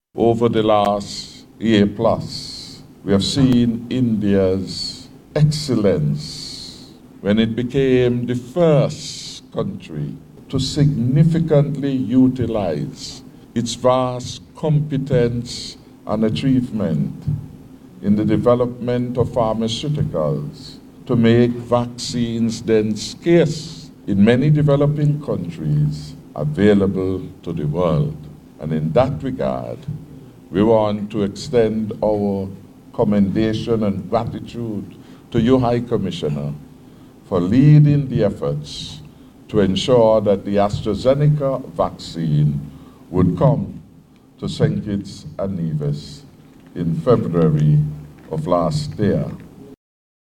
The event was held at the Tiranga Indian Restaurant in St. Kitts on Sunday, February 6th, 2022.
Prime Minister Harris elaborated on one significant contribution made by the government of India to assist the people and Government of St. Kitts and Nevis during the celebration of India’s 73rd anniversary of Independence: